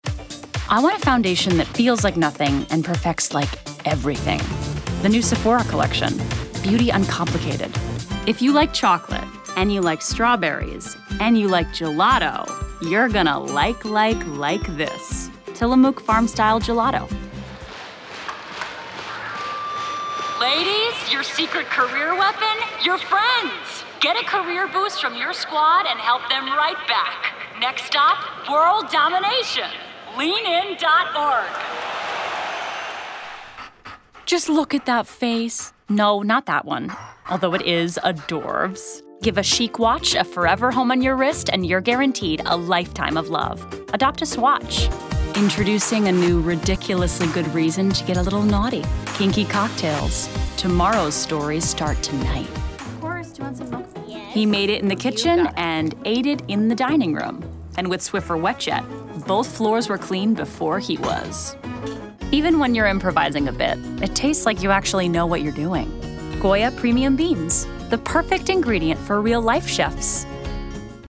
Demo
Young Adult, Adult
Has Own Studio
standard us | natural
cool
quirky
smooth/sophisticated
warm/friendly